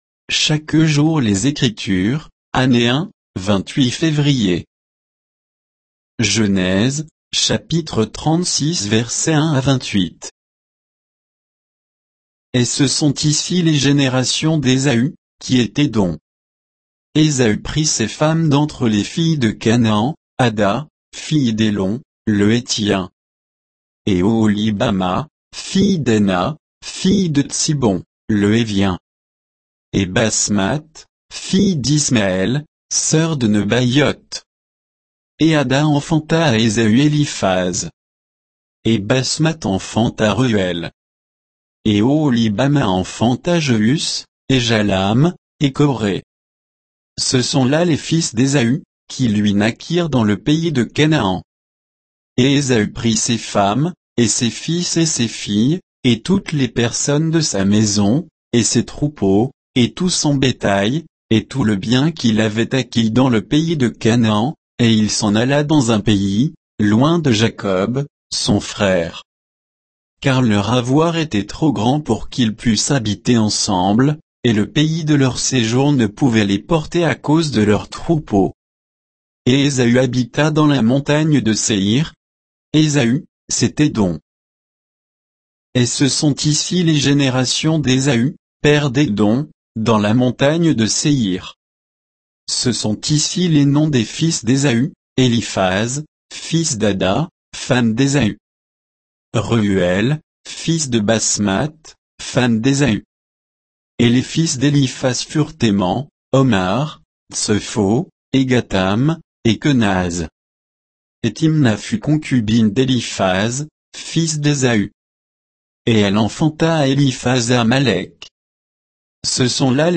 Méditation quoditienne de Chaque jour les Écritures sur Genèse 36